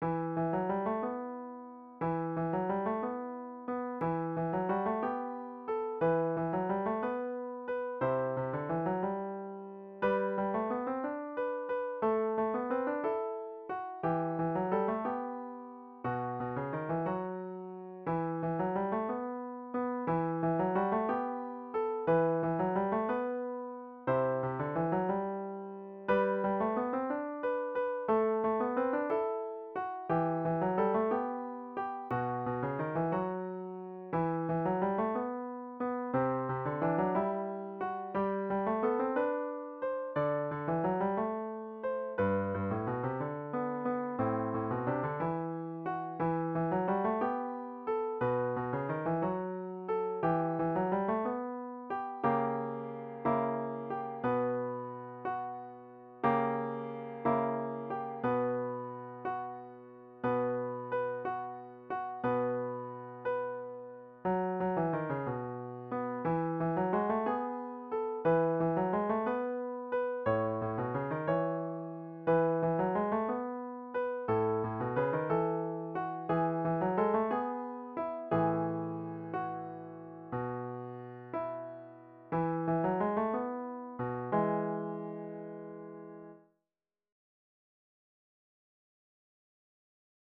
Children’s Choir and Piano